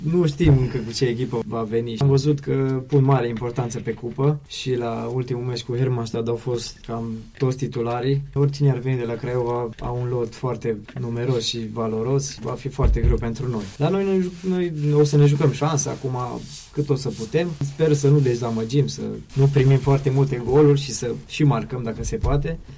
jucător al echipei de fotbal CS Ocna Mureș.